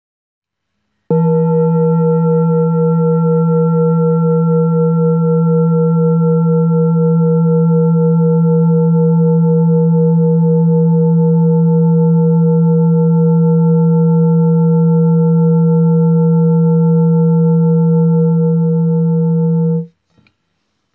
High Quality Peter Hess Singing Bowls, Hand Hammered Clean Finishing M , A bowl used for meditation and healing, producing a soothing sound that promotes relaxation and mindfulness
Singing Bowl Ching Lu Kyogaku
Material 7 Metal Bronze